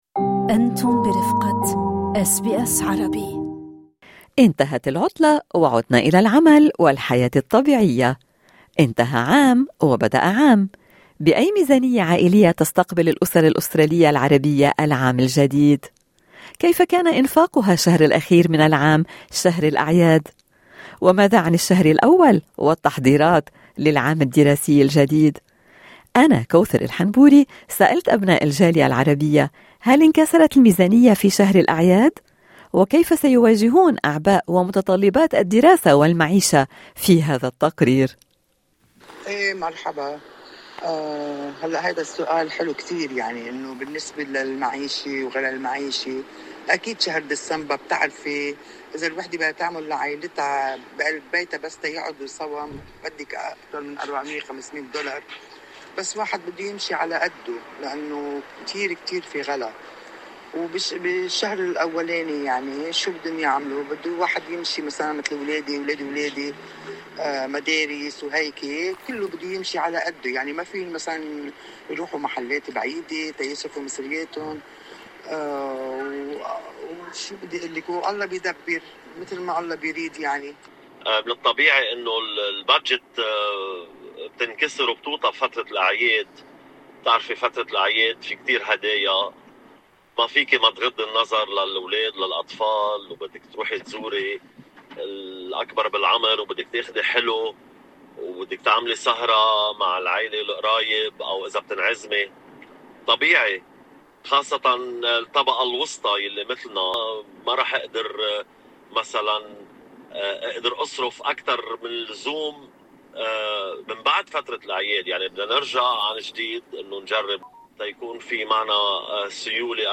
كيف كان أنفاقها شهر الأعياد وماذا عن الشهر الأول والتحضيرات للعام الدراسي الجديد؟ استطلعنا أراء ابناء الجالية العربية: